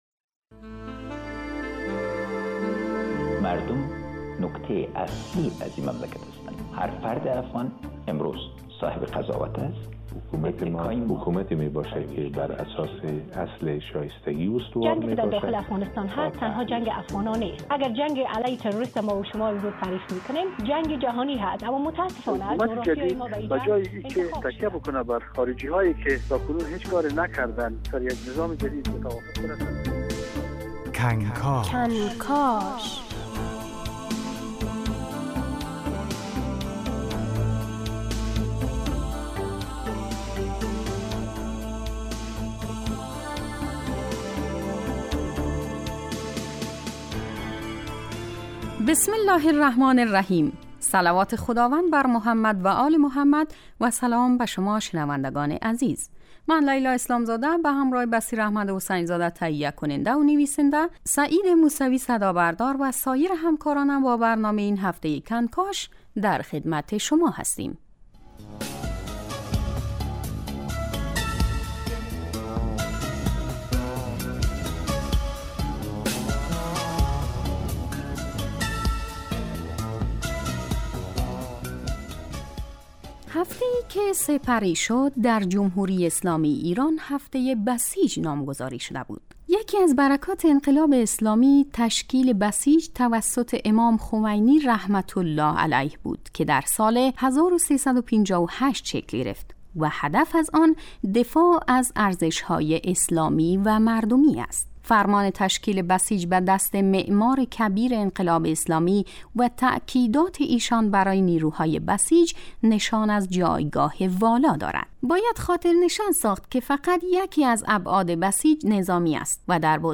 حضور رزمندگان بسیجی افغانستان در عرصه دفاع مقدس در گفت و گو با نویسندگان و کارشناسان دفاع مقدس.